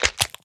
Minecraft Version Minecraft Version latest Latest Release | Latest Snapshot latest / assets / minecraft / sounds / mob / turtle / egg / egg_crack4.ogg Compare With Compare With Latest Release | Latest Snapshot
egg_crack4.ogg